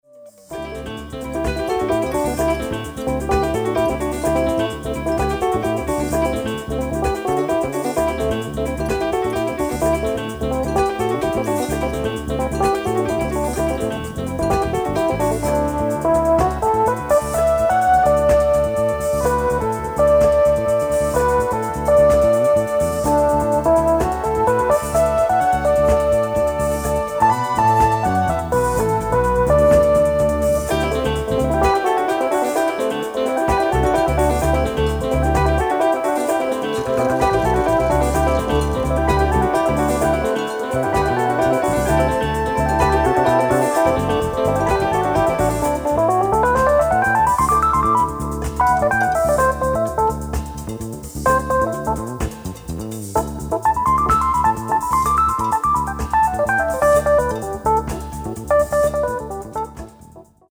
piano, fender rhodes, synthesizers
fretless electric bass
drums